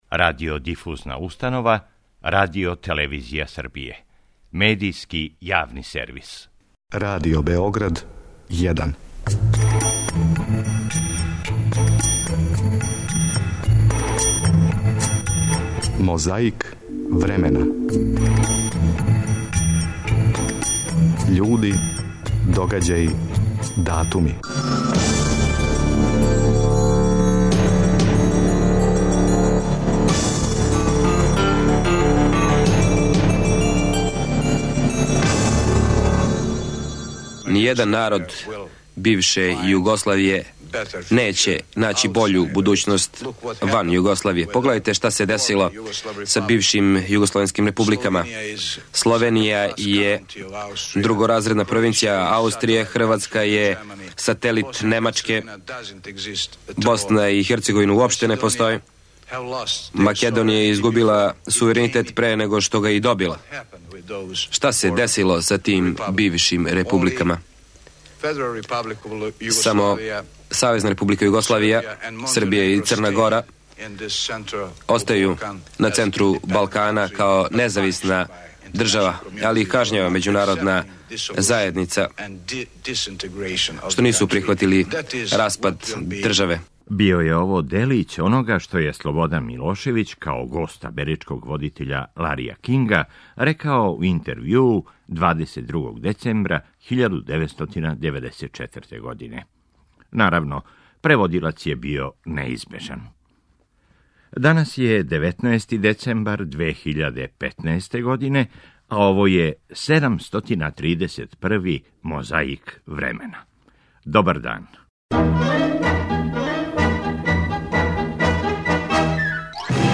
Фронт код Окучана. Екипа Радио Новог Сада и ратни извештач интервјуишу борце.